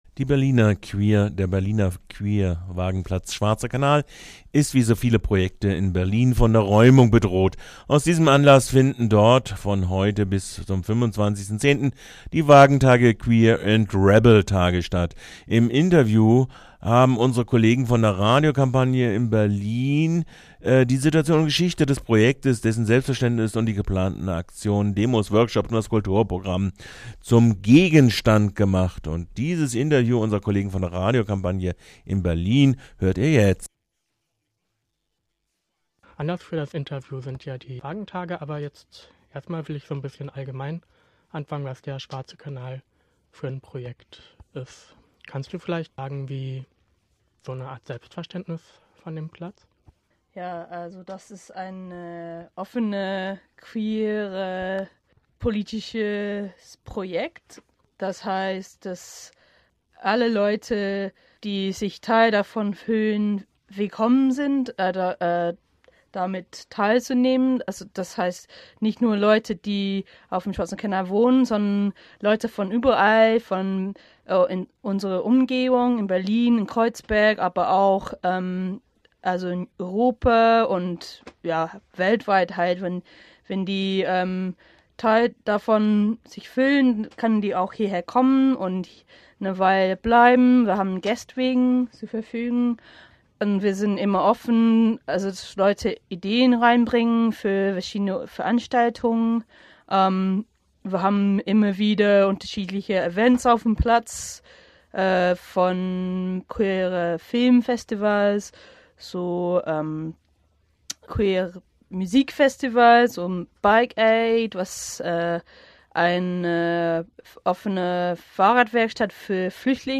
Interview Wagenplatz schwarzer Kanal Berlin